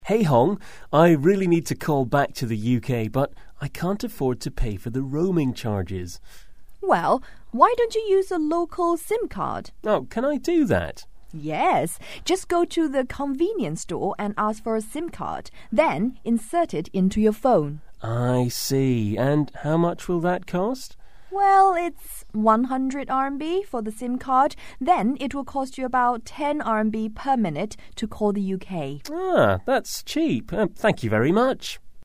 英语初学者口语对话第21集：用当地的手机用户识别卡吧